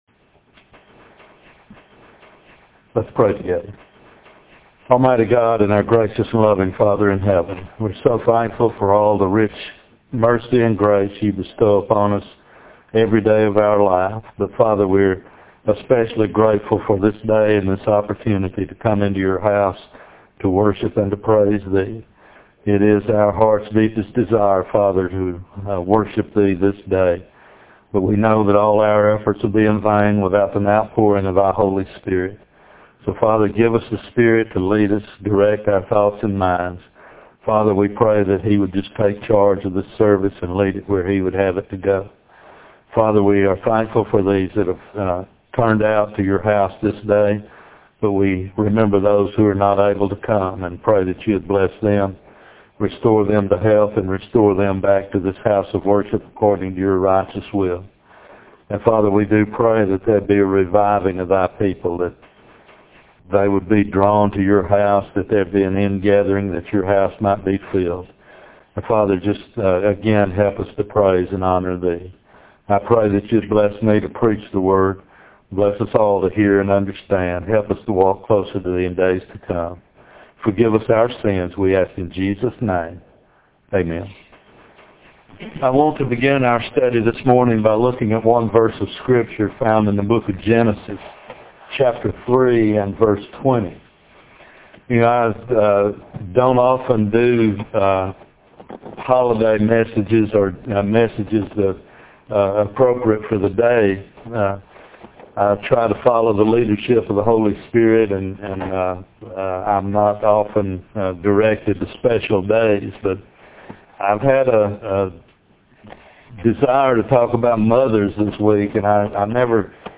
Genesis 3:20, Eve, The Mother Of All Jun 8 In: Sermon by Speaker